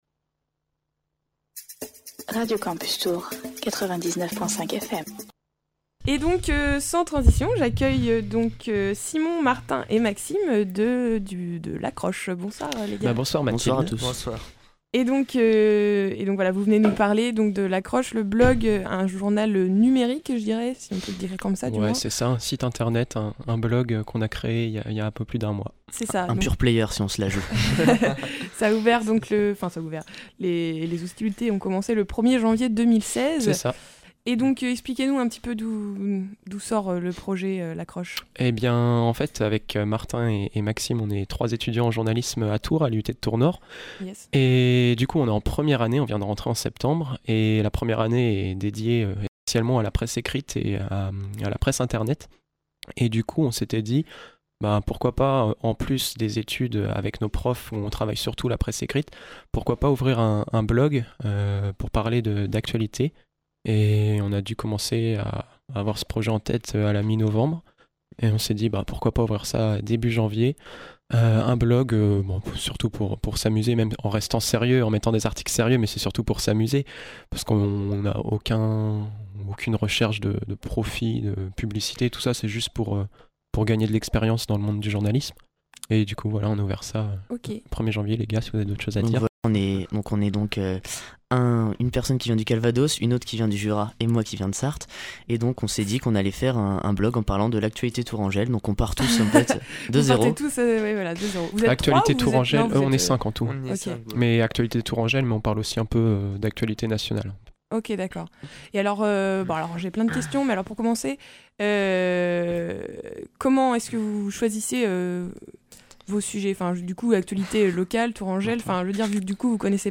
Toute l’équipe de L’accroche était à mes côtés ce soir dans Sortez ! pour vous présenter le nouveau journal numérique. Lancé le 1er Janvier 2016, L’accroche vous propose un nouveau regard sur l’actualité, qu’elle soit locale, nationale ou même internationale.